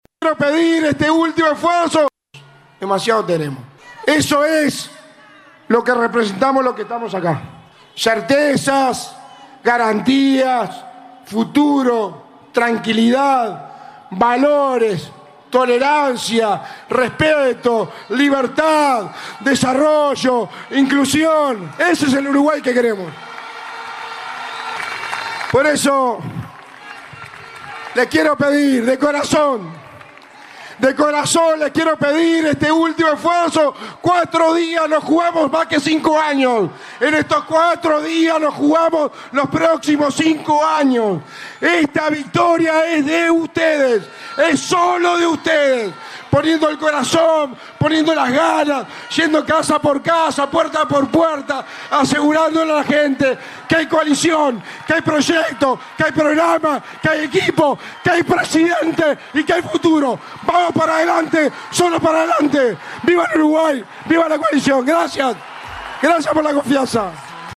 El candidato presidencial de la Coalición Republicana, Álvaro Delgado en el acto de cierre de campaña en el Obelisco de Montevideo le envió un mensaje a la militancia: “”en 4 días nos jugamos más que 5 años, esta victoria es de ustedes, solo de ustedes».
2-delgado-acto-de-cierre.mp3